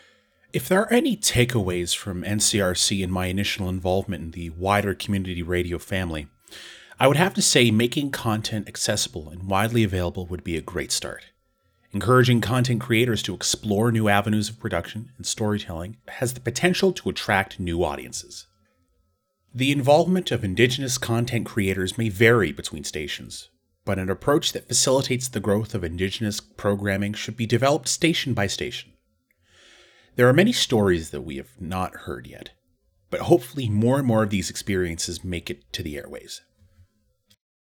Type: Commentary
320kbps Stereo